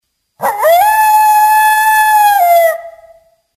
Звуки койота
Воет